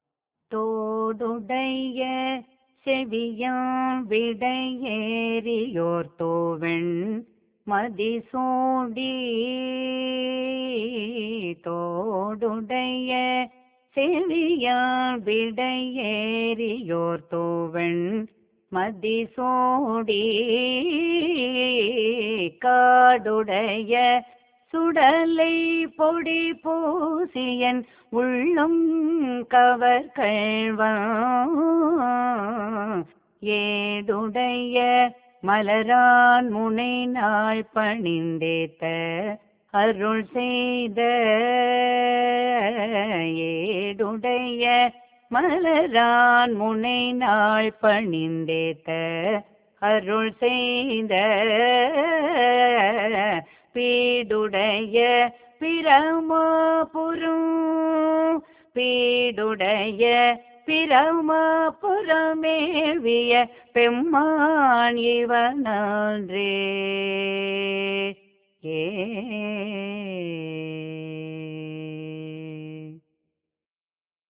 பண் : நட்டபாடை